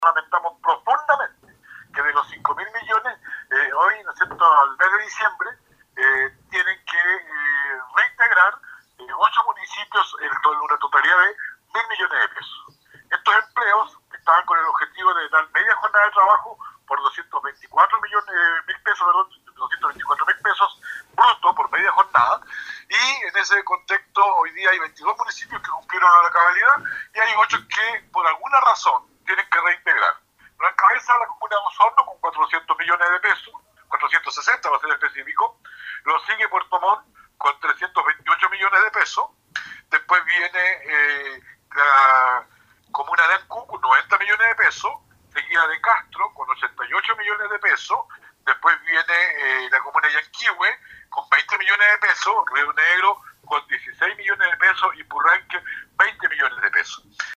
Así lo estableció en diálogo con radio Estrella del Mar el presidente del Core, Juan Cárcamo.